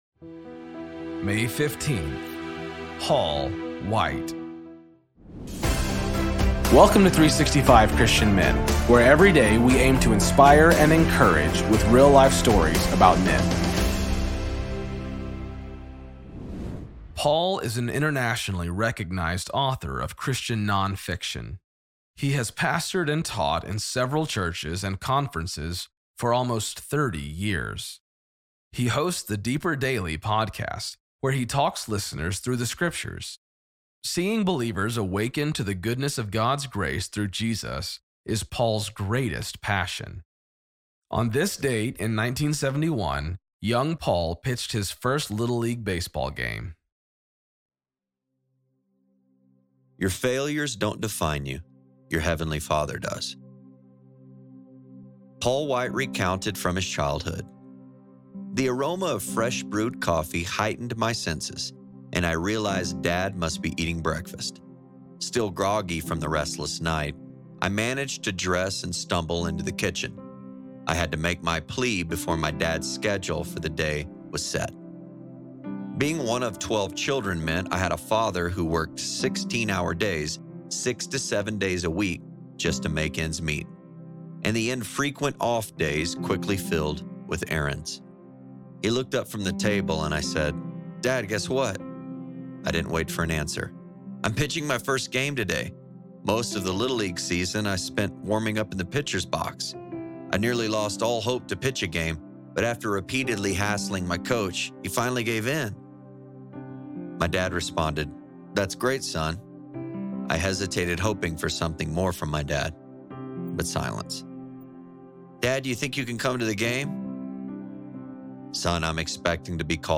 Story read